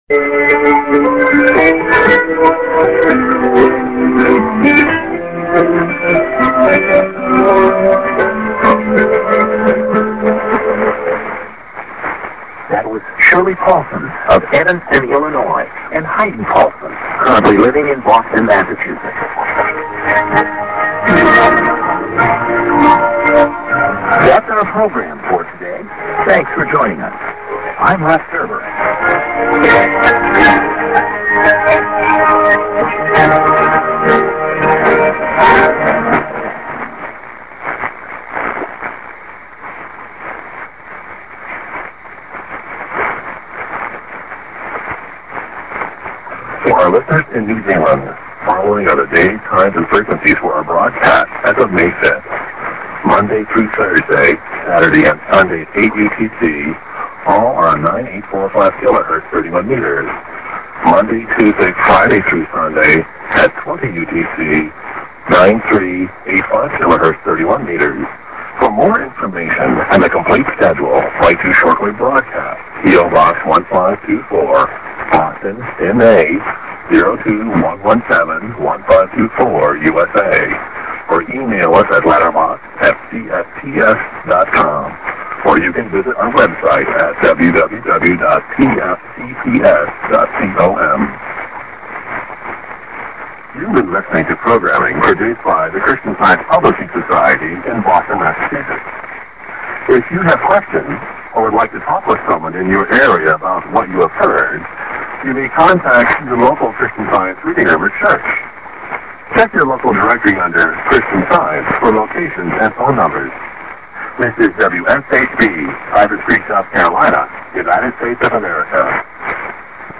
music->ID->SKJ->ADDR(post+Web)->ID(man) s/off